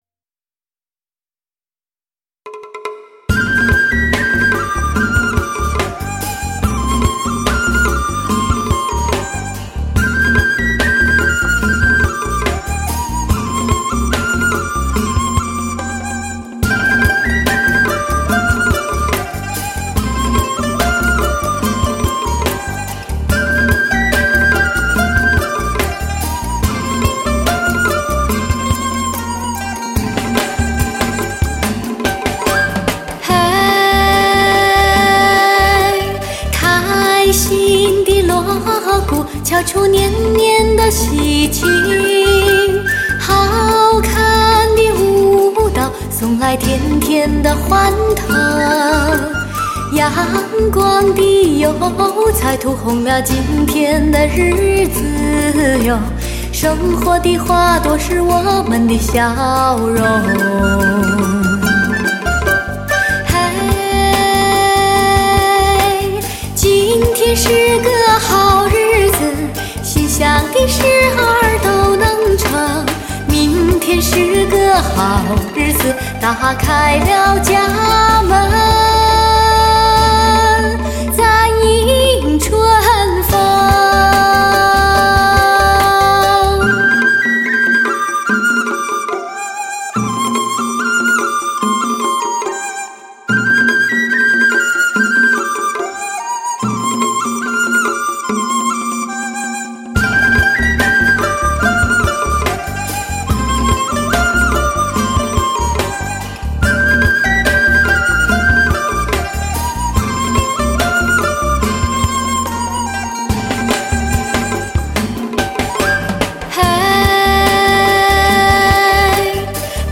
声声响亮动人，耳熟能详的旋律演绎发烧HI-FI精品。